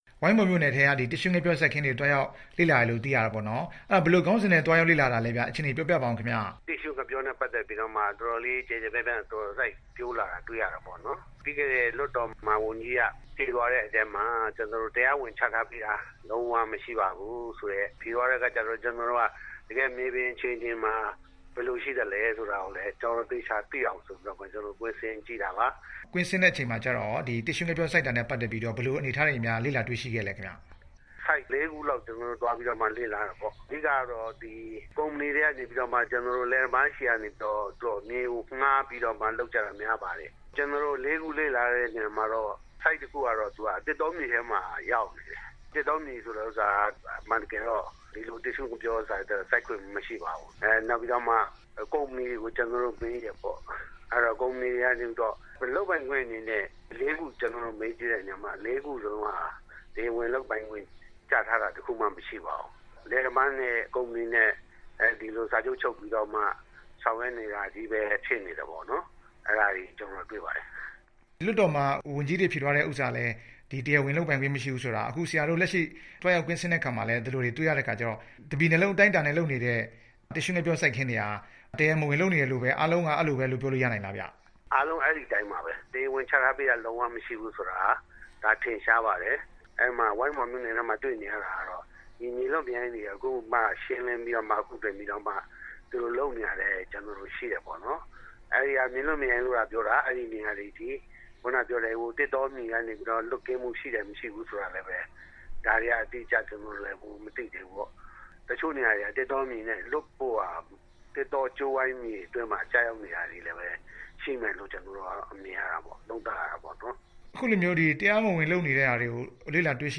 ဒီကနေ့ ဆက်သွယ်မေးမြန်းထားပါတယ်။